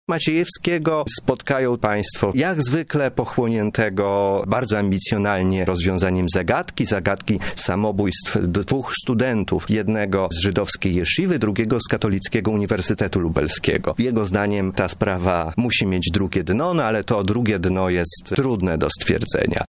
O szczegółach mówi autor książki, Marcin Wroński.